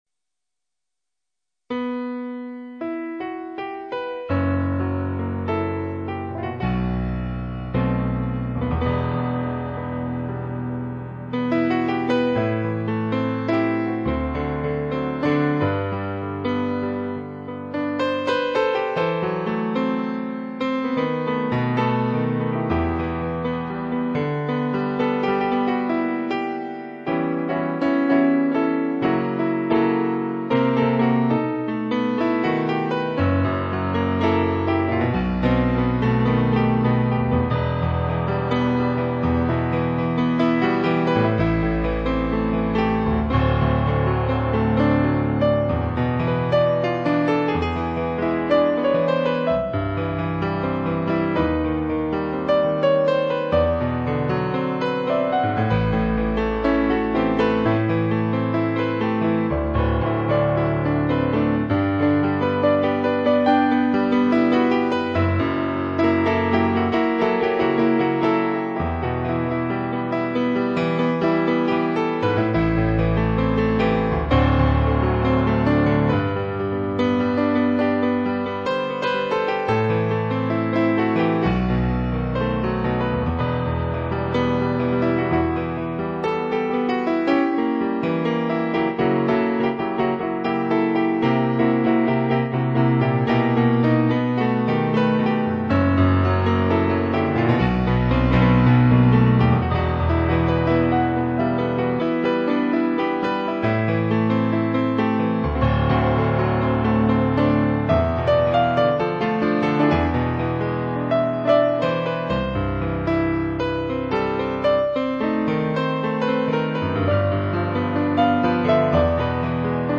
Here are songs in MP3 played by me: